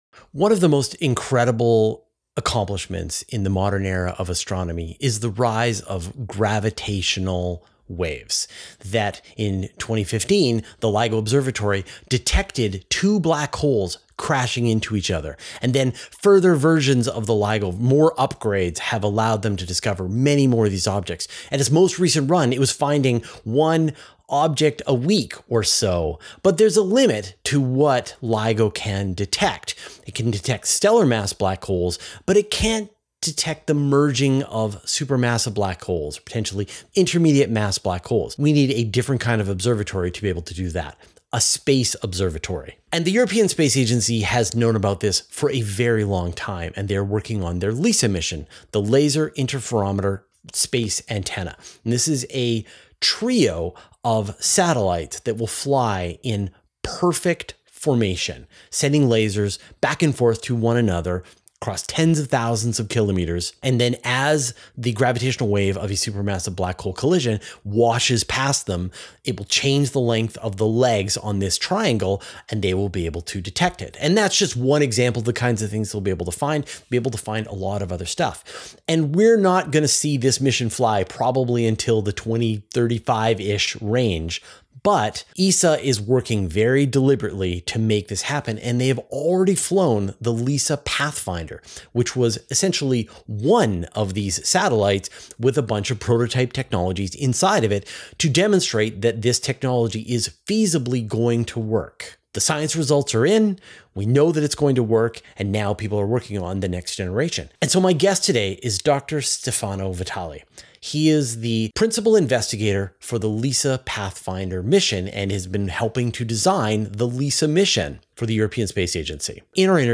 [Interview+] Building LISA, Humanity's Biggest Telescope 53 minutes Posted Jan 19, 2026 at 11:18 pm .